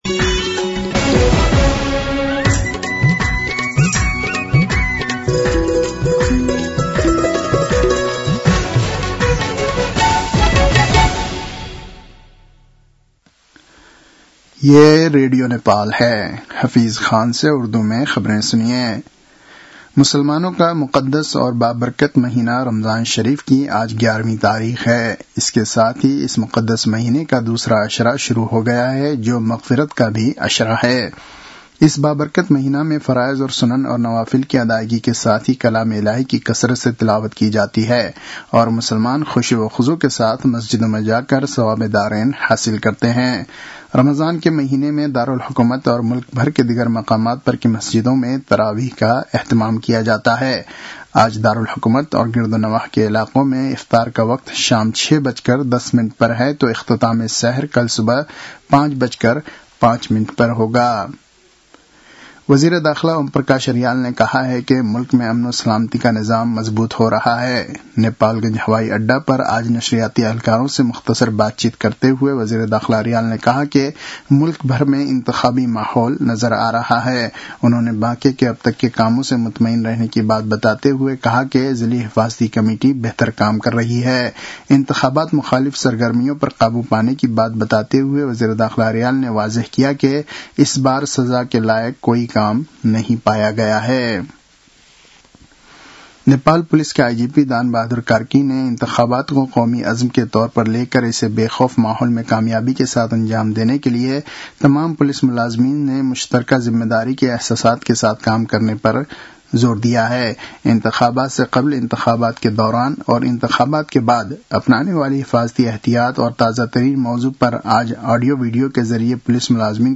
उर्दु भाषामा समाचार : १७ फागुन , २०८२